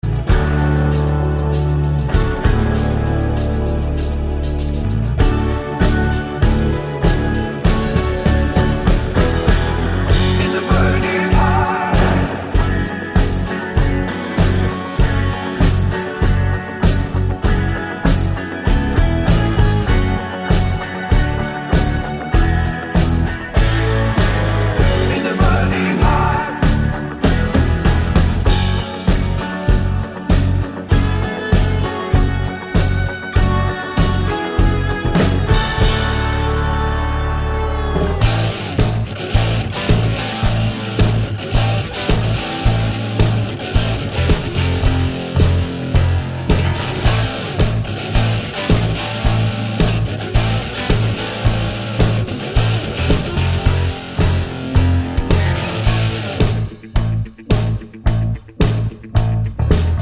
Twofers Medley Down 3 Semitones Rock 3:12 Buy £1.50